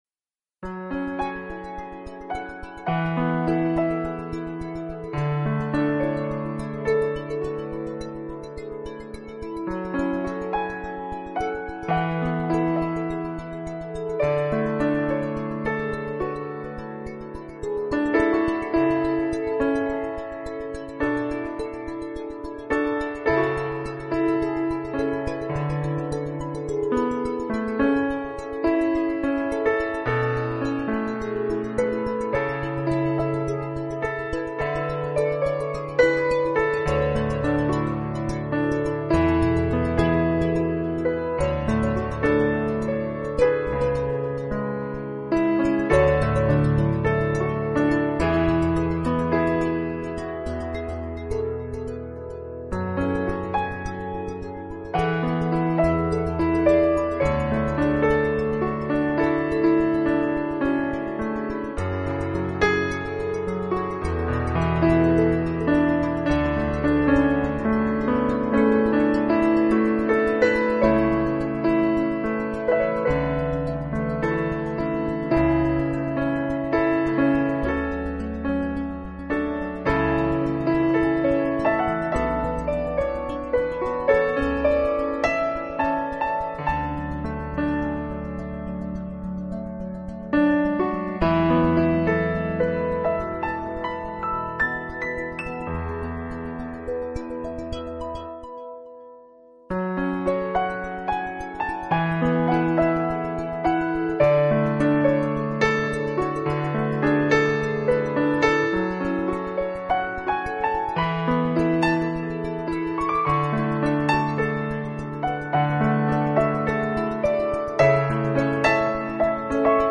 【纯美钢琴】